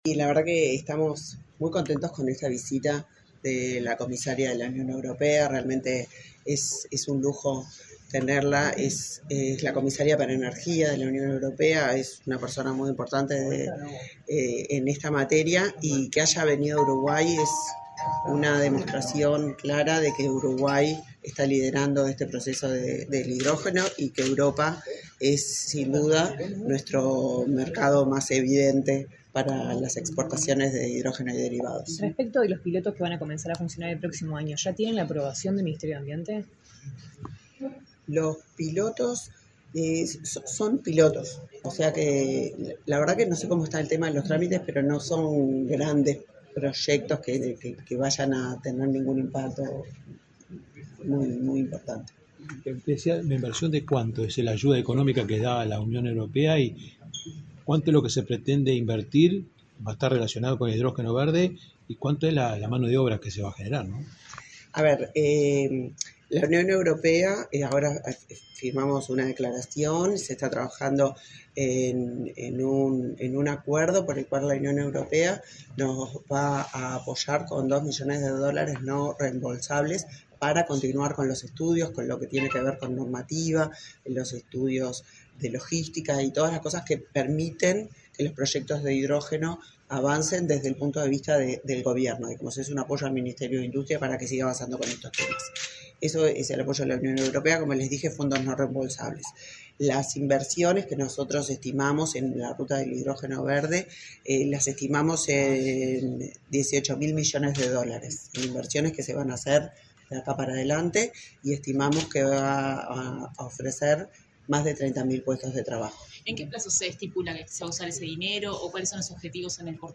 Declaraciones de la ministra de Industria, Elisa Facio
La ministra de Industria, Elisa Facio, dialogó con la prensa, luego de participar en la apertura del V Foro de Inversión Europea en Uruguay: